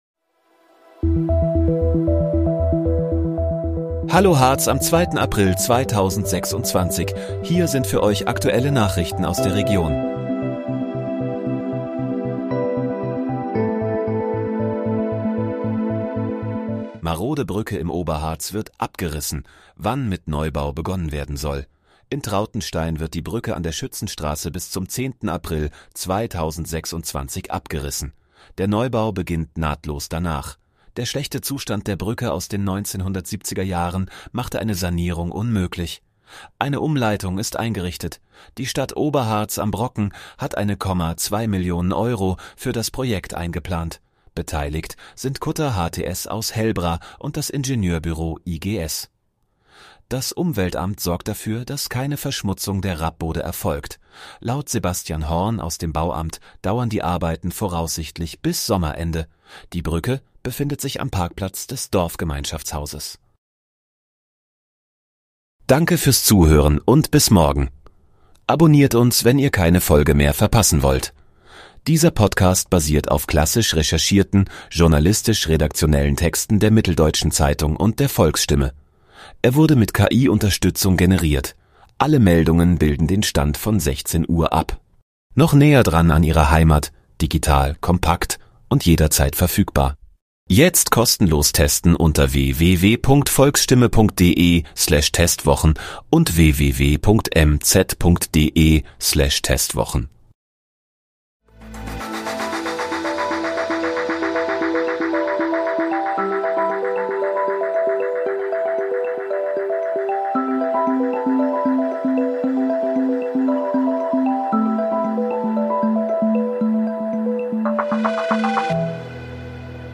Hallo, Harz: Aktuelle Nachrichten vom 02.04.2026, erstellt mit KI-Unterstützung